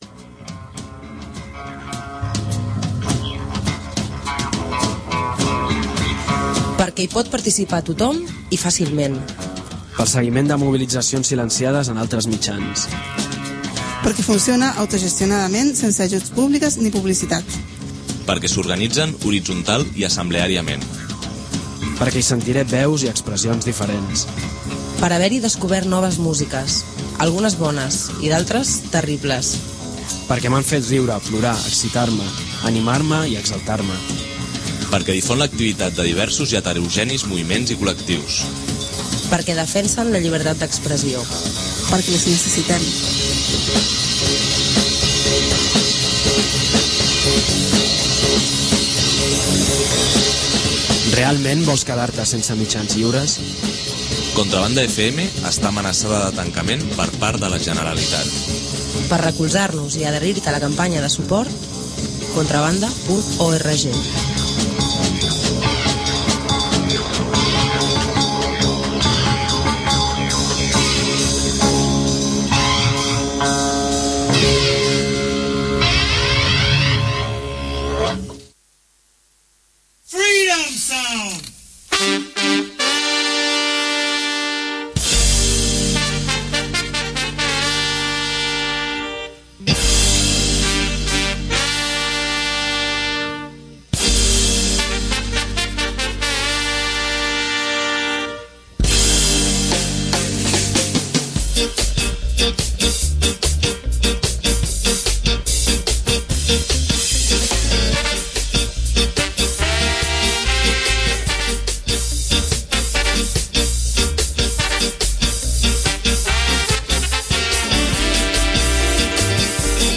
banda de early-reggae de Detroit.
hard-rocking-two-tone
banda de skinhead reggae